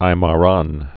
(īmä-rän)